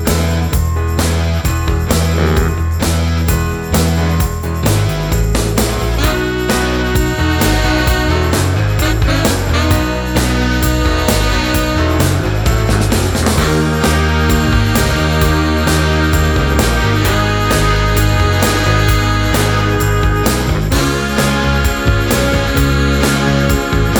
no Backing Vocals Duets 3:12 Buy £1.50